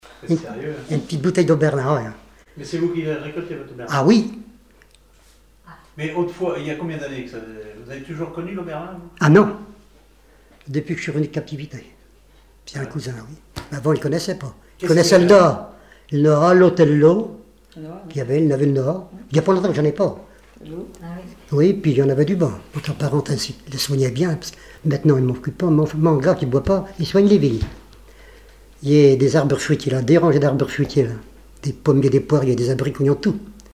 regroupement de chanteurs locaux
Catégorie Témoignage